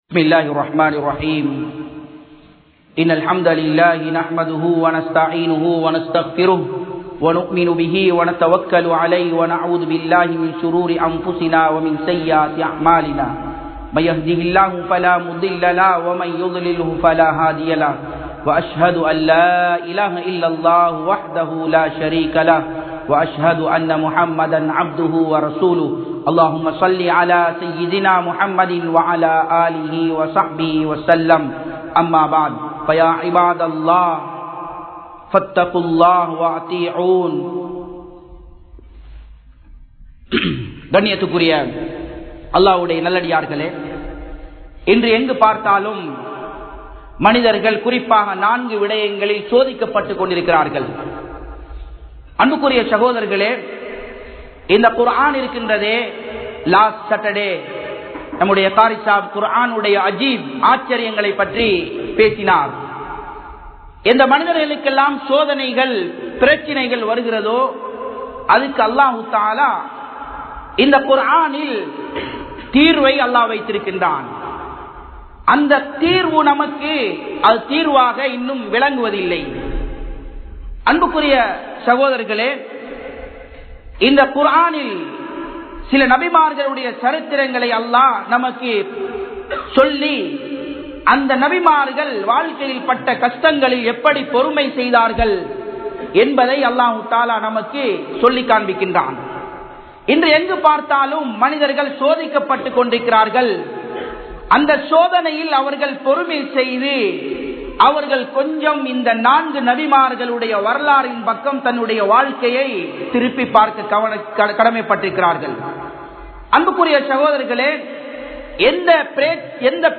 Kavalaihal Neenga Veanduma? (கவலைகள் நீங்க வேண்டுமா?) | Audio Bayans | All Ceylon Muslim Youth Community | Addalaichenai
Japan, Nagoya Port Jumua Masjidh 2017-11-17 Tamil Download